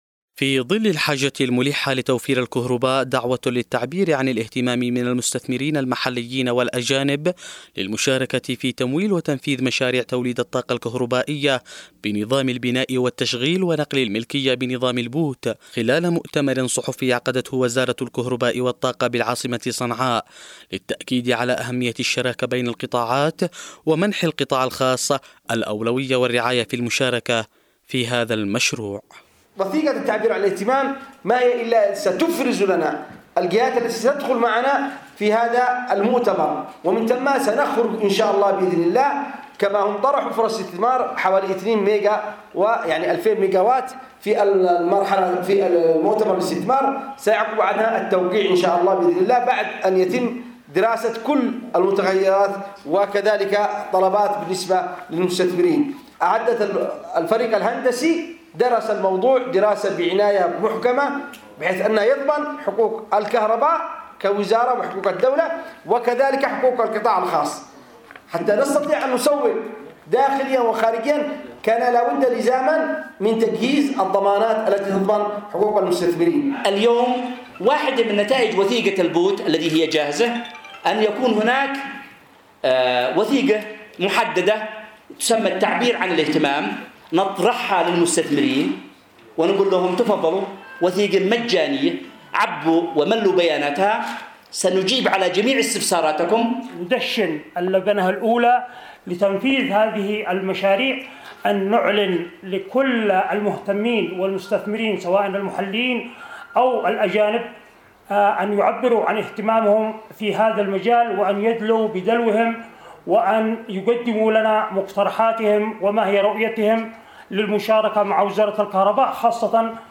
مؤتمر صحفي لوزارة الكهرباء حول الاستثمار بمشاريع الطاقة بنظام “البوت”